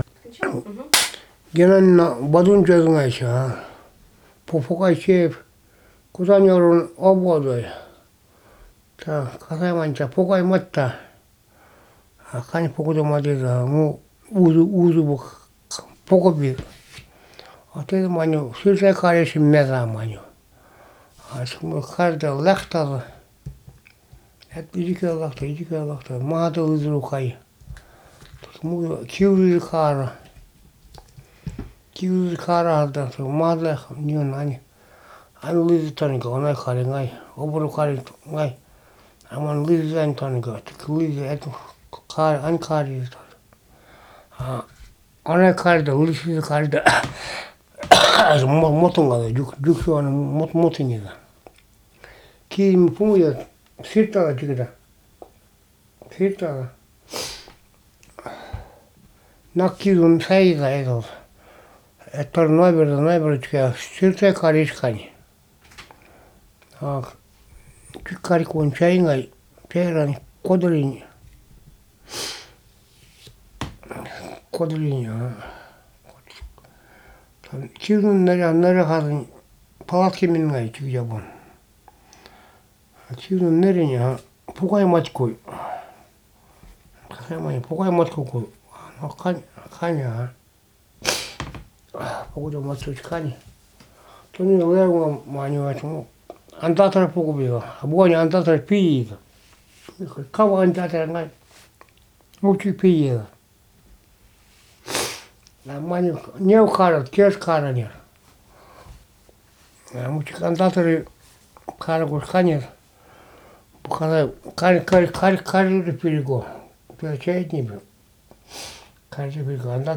2. Аудиозаписи речи